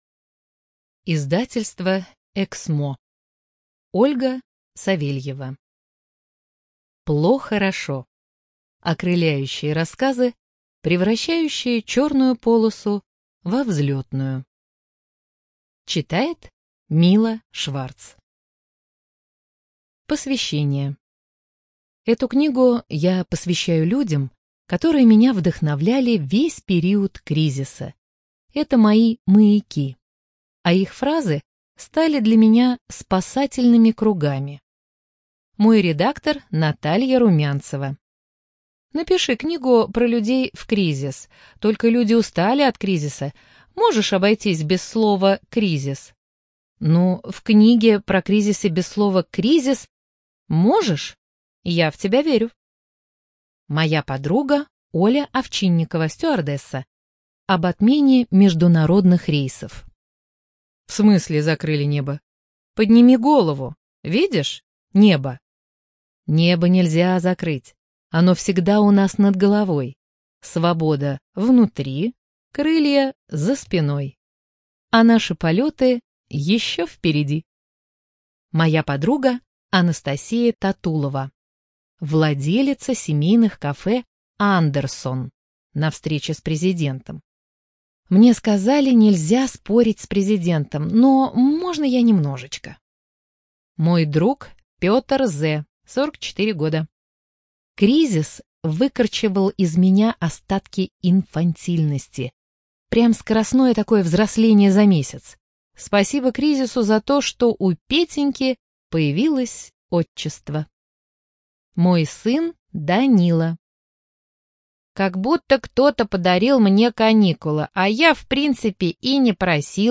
Студия звукозаписиЭКСМО